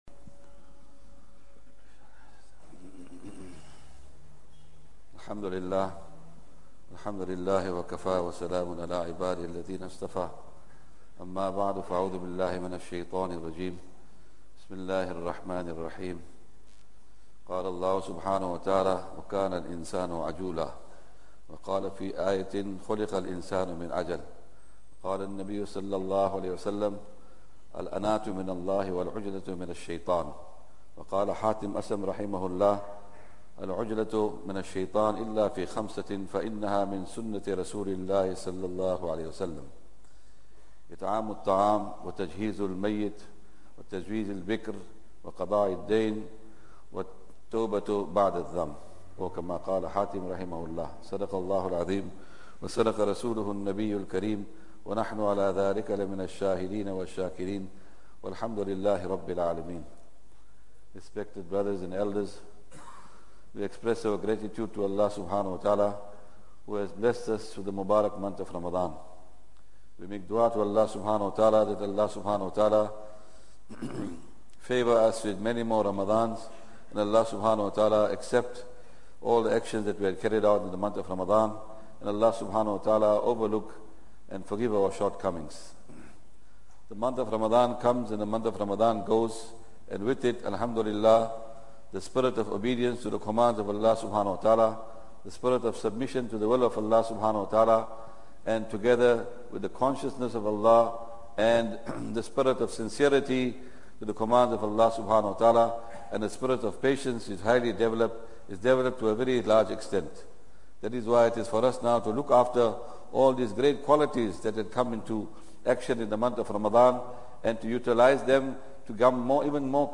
Jumuah